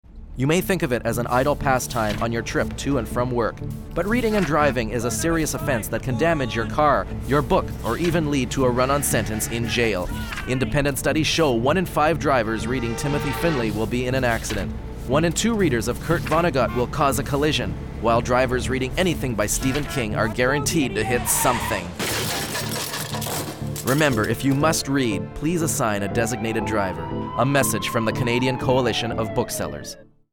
VOICEOVER ON READING WHILE DRIVING